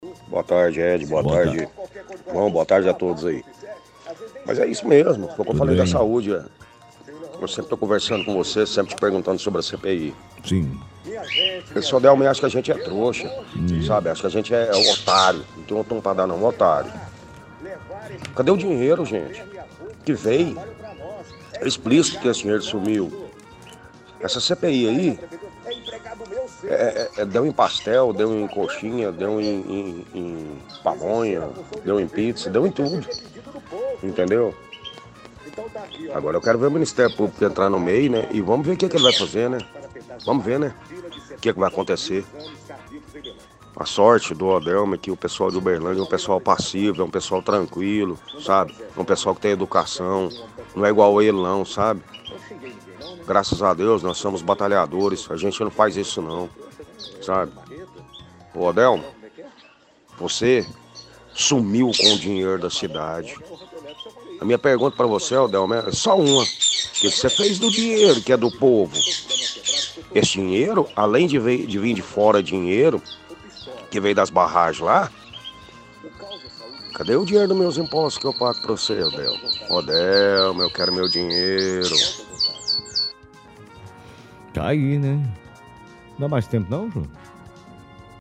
Mensagens dos ouvintes – Cadê o dinheiro
– Ouvinte questiona cadê o dinheiro que foi enviado para a saúde e questiona porque o ministério público não investiga.